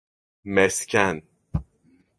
Pronúnciase como (IPA) /mesˈcen/